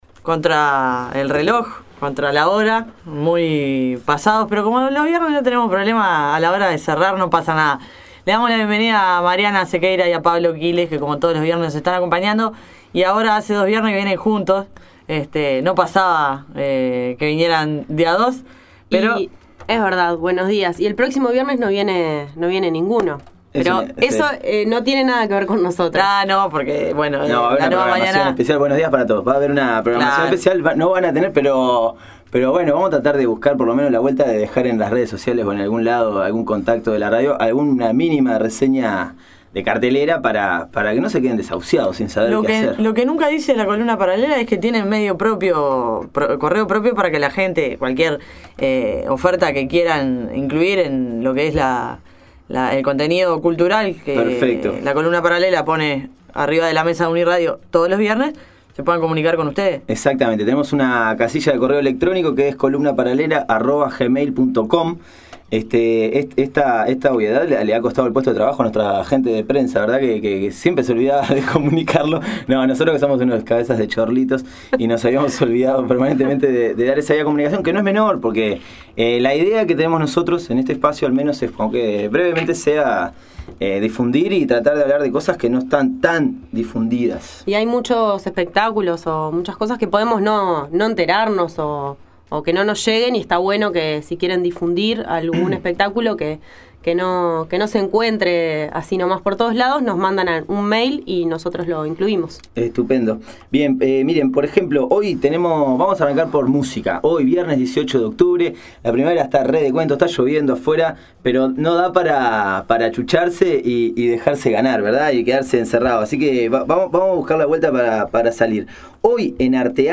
Audio: Musica popular e instrumental brasilera en Uruguay. Entrevista a la banda La Chorona.